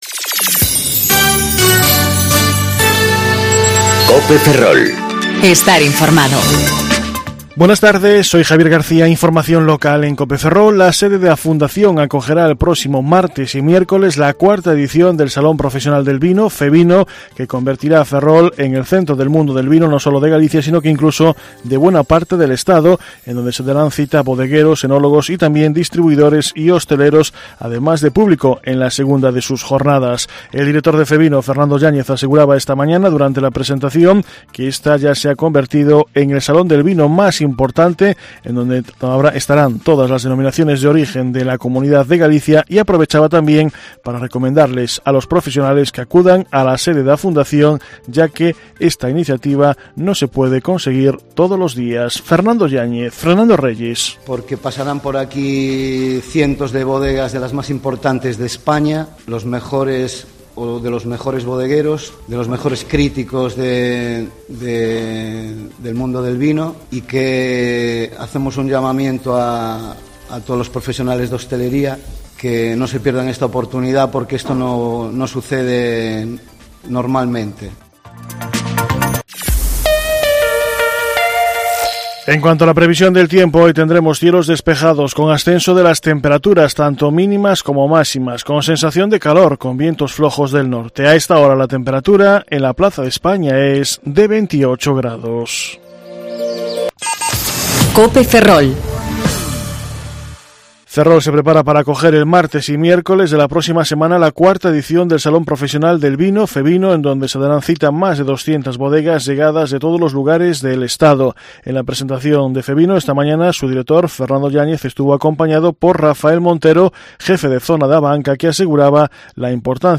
Informativo Mediodía Cope Ferrol 30/04/2019 (De 14.20 a 14.30 horas)